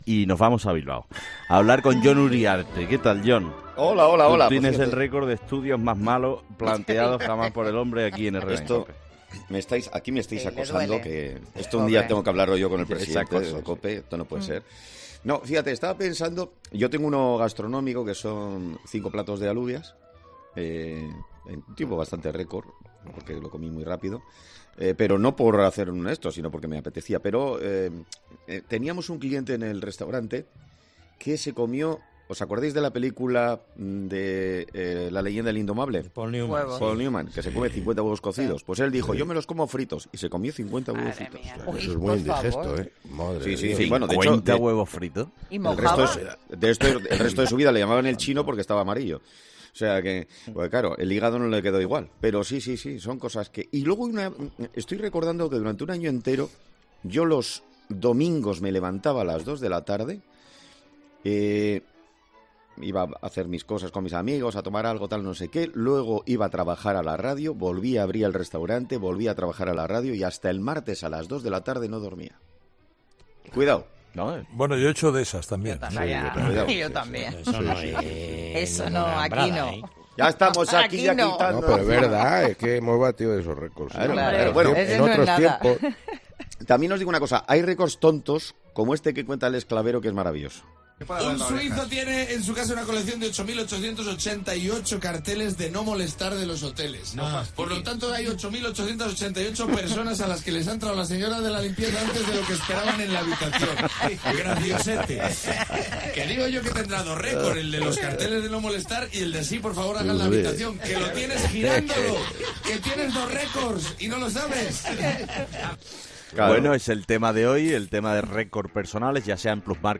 Entre risas